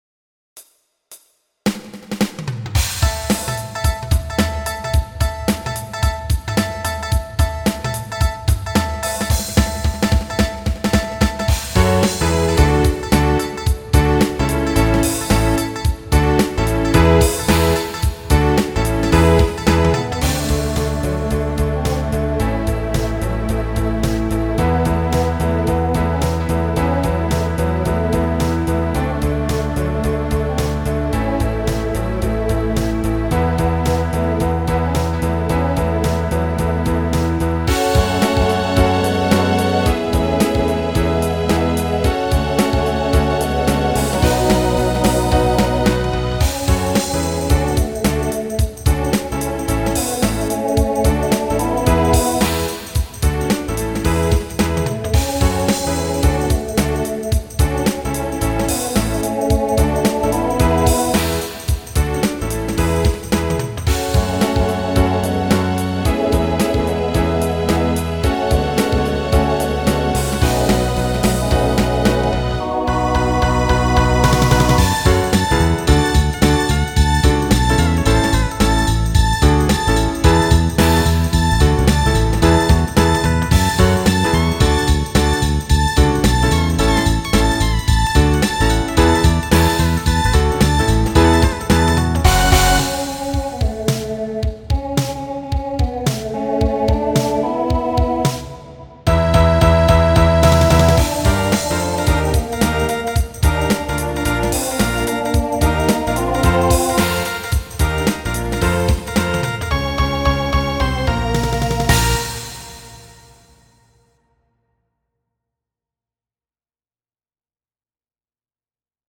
Voicing TTB Instrumental combo Genre Rock